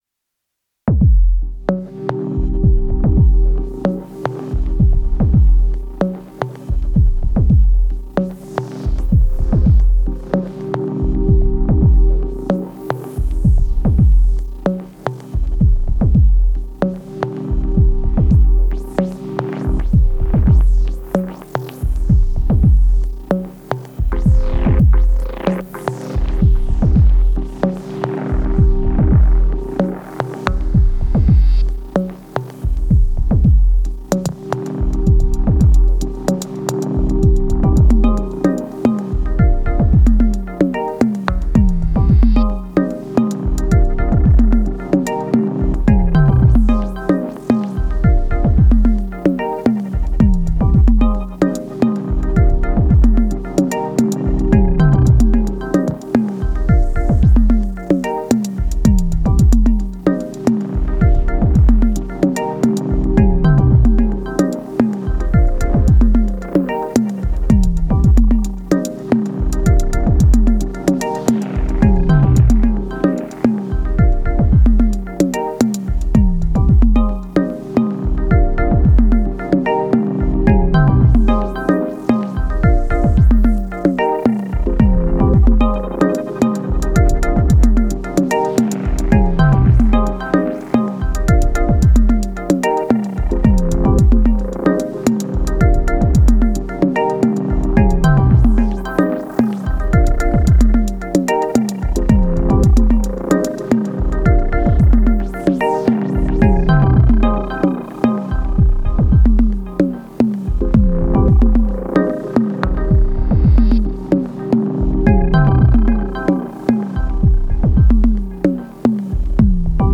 My first jam with the AR mk2. Lame and muddy but it’s a start :slight_smile: